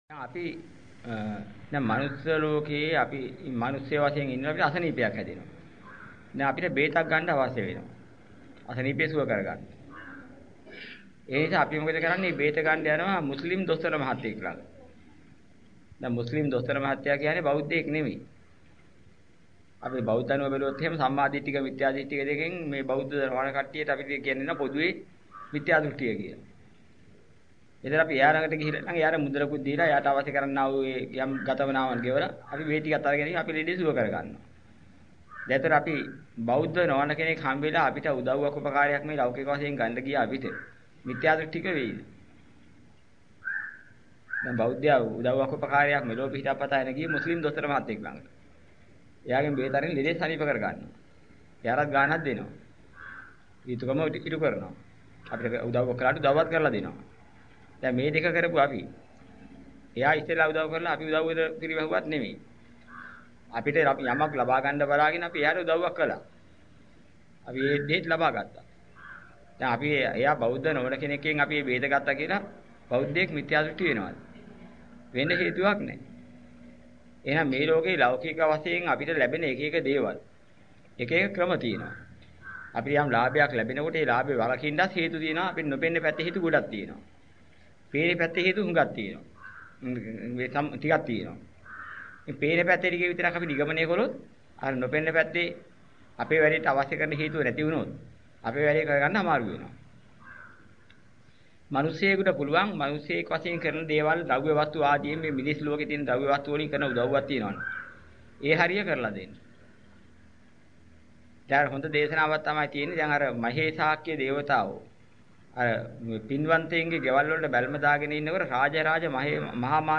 ධර්ම දේශනා.